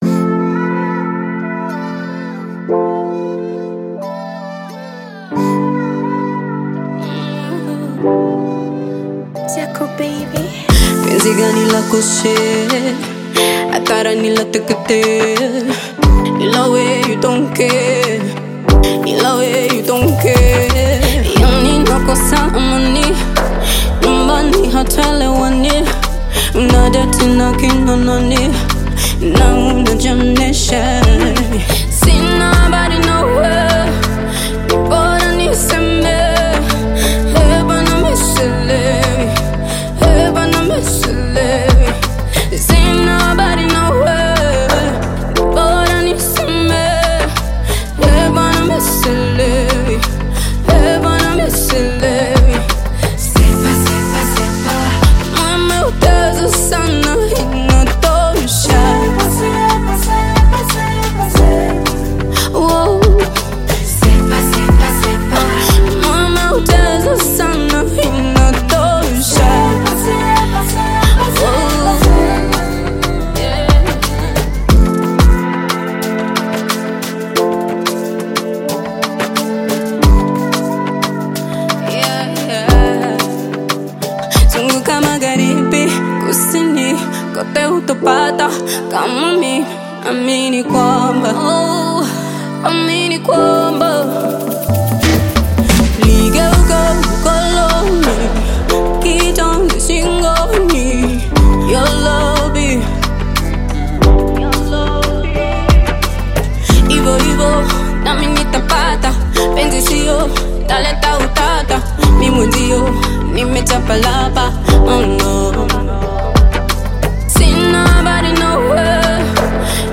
Tanzanian upcoming bongo flava artist
Gospel song